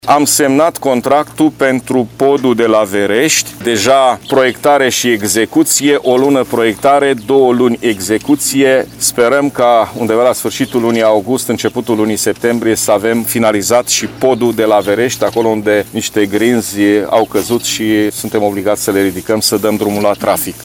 Președintele GHEORGHE FLUTUR a declarat astăzi că la toamnă traficul ar putea fi reluat.